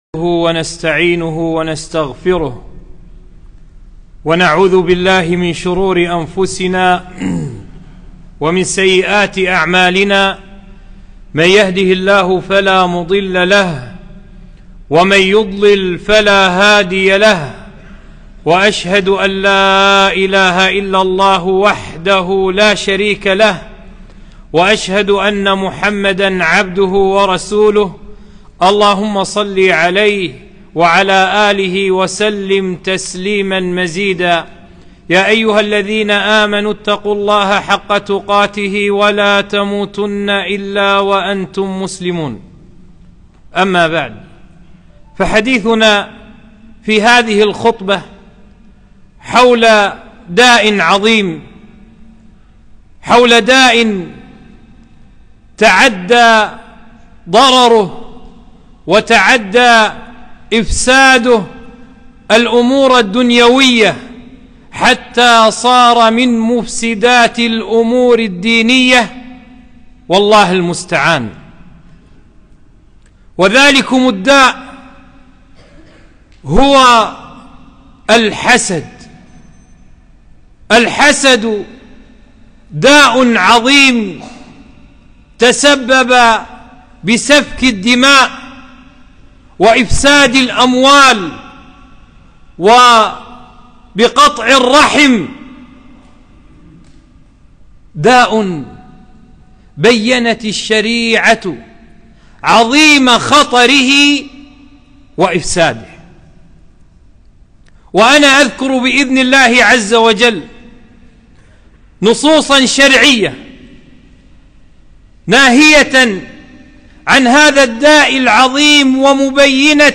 خطبة - لا تحاسدوا